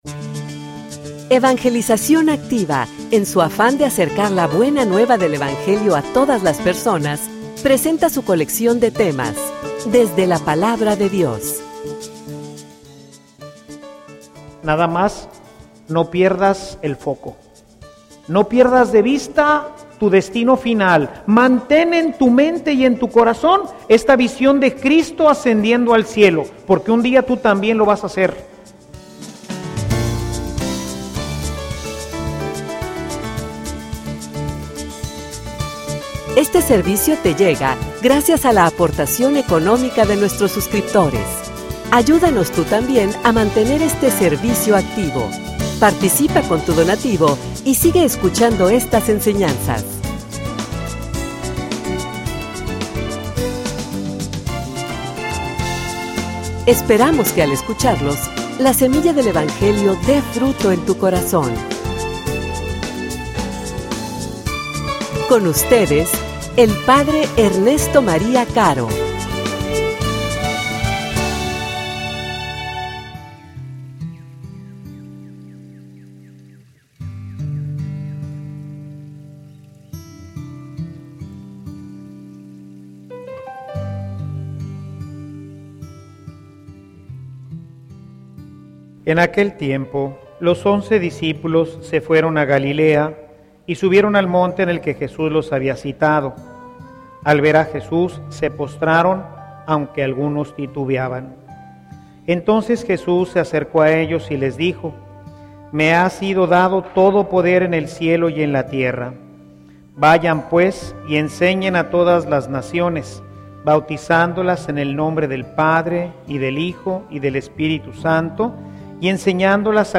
homilia_No_pierdas_el_foco.mp3